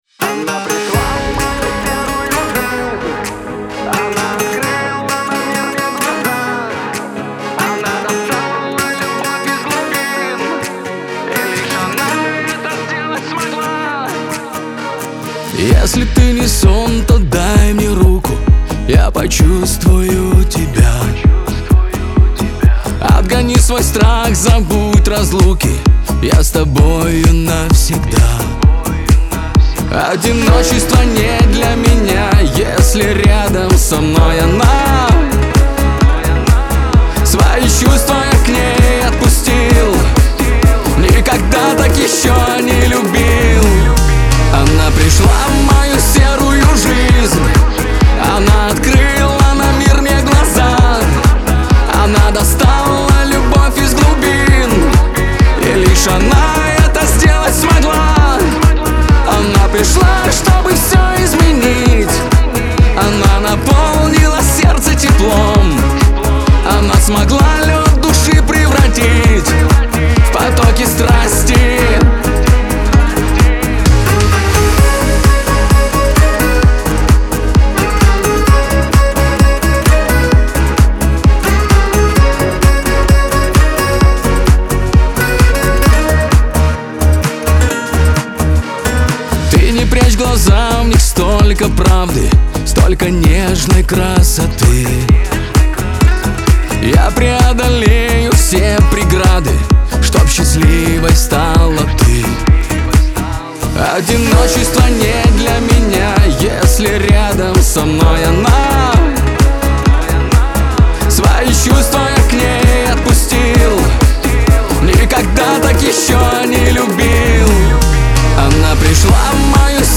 pop , эстрада